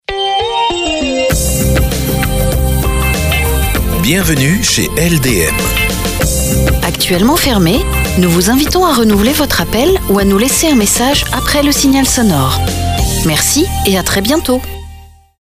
Message répondeur professionnel